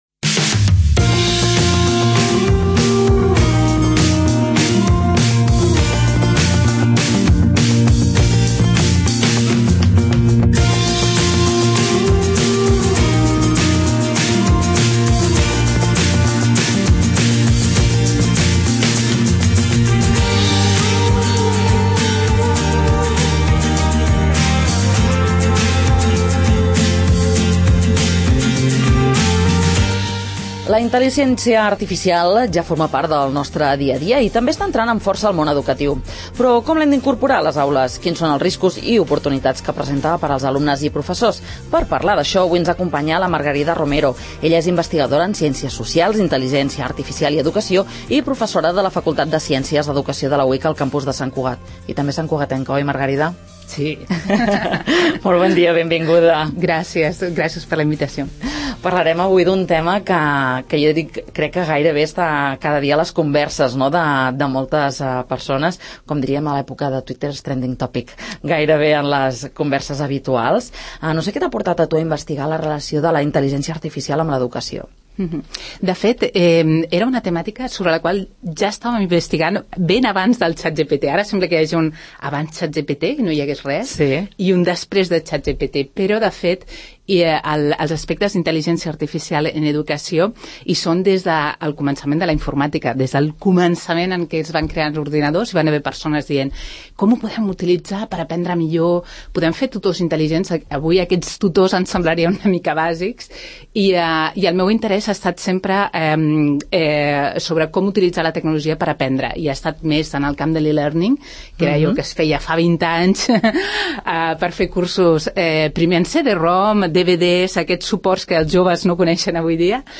Intel�lig�ncia Artificial i Educaci� a l'estudi Ramon Barnils de R�dio Sant Cugat